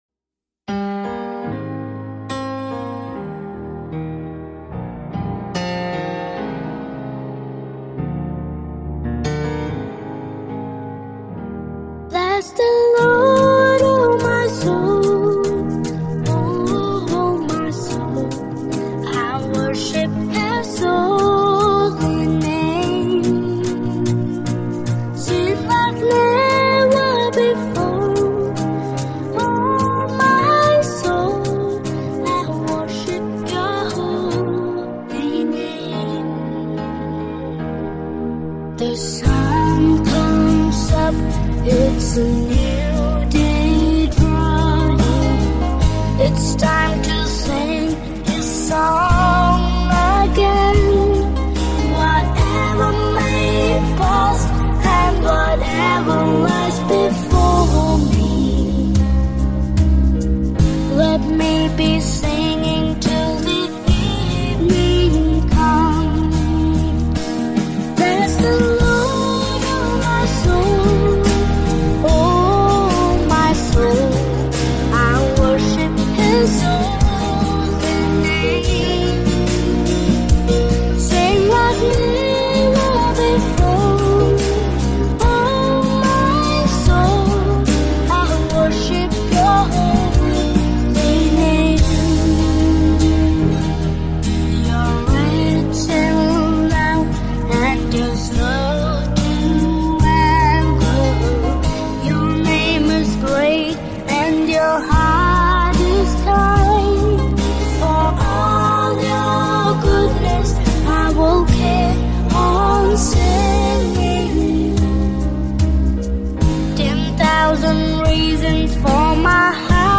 *Converted for key of Bb instrument